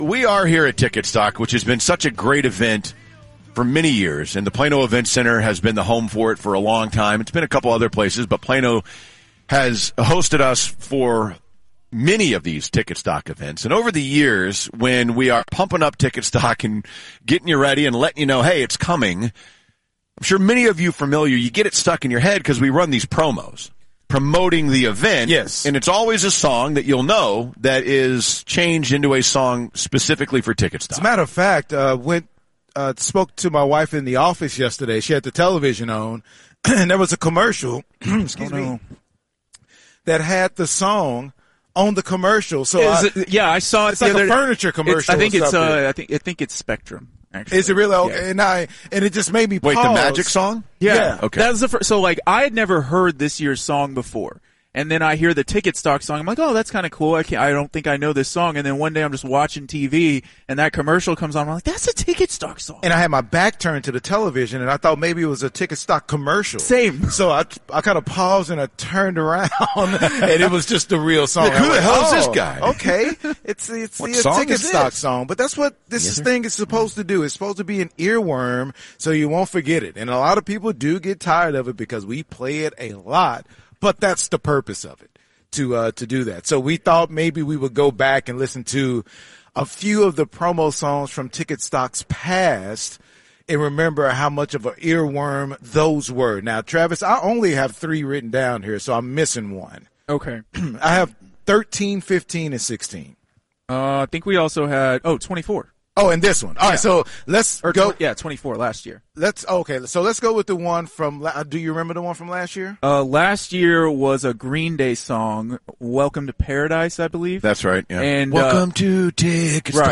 Promo songs and Timewasters performances from past Ticketstocks.